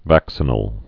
(văksə-nəl, văk-sē-)